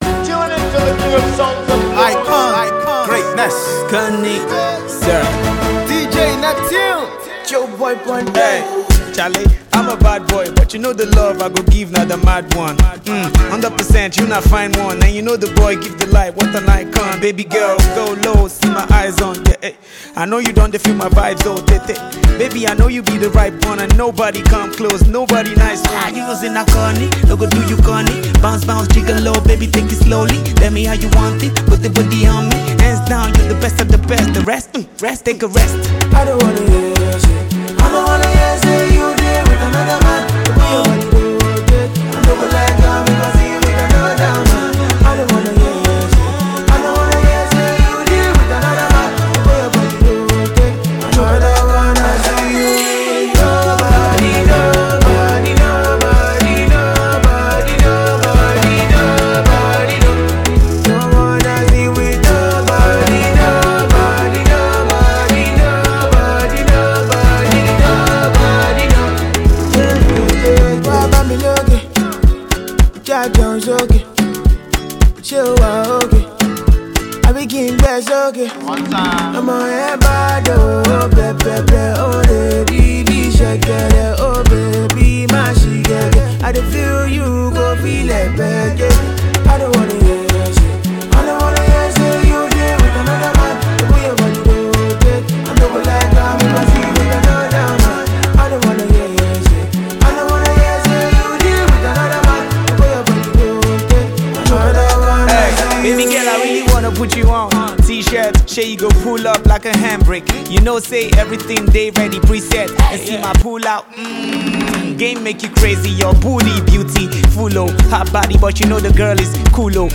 rap verses